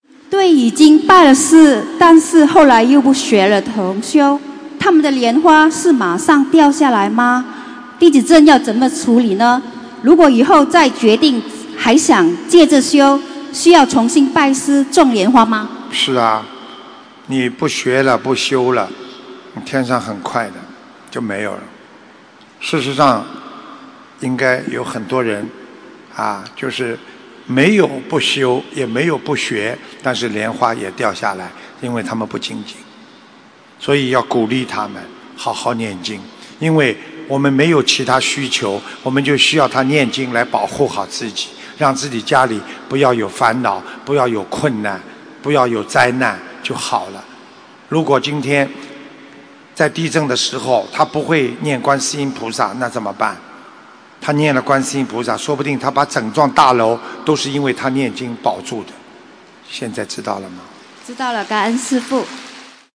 精进修行莲花才不会掉下来┃弟子提问 师父回答 - 2017 - 心如菩提 - Powered by Discuz!